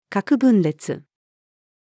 核分裂-nuclear-fission-female.mp3